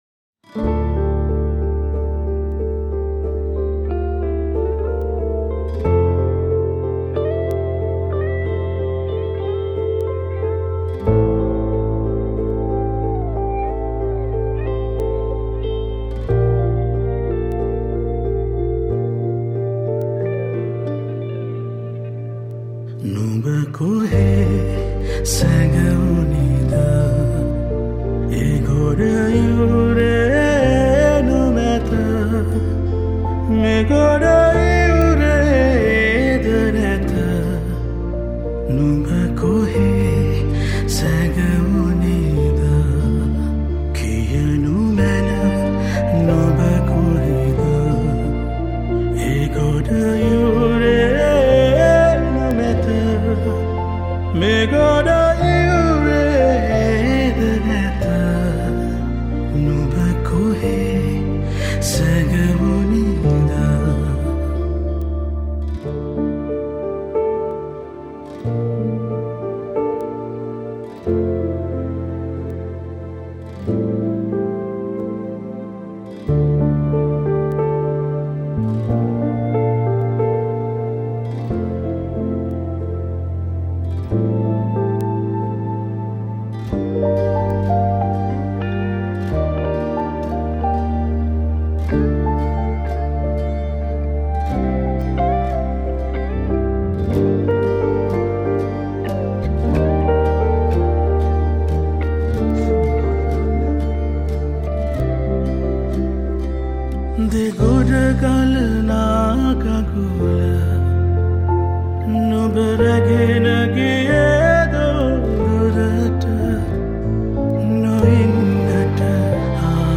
Piano
Keys
Guitars